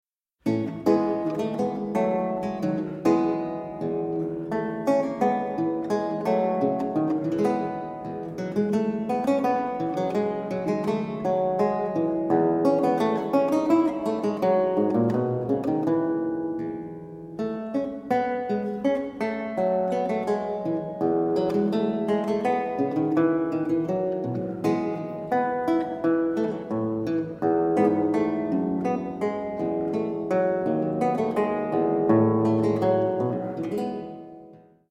Italská kytarová a theorbová hudba ze 17. století
Kaple Pozdvižení svatého Kříže, Nižbor 2014
Tiorba sola